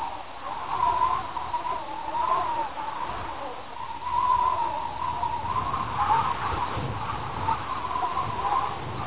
Slaby vietor - dokola.wav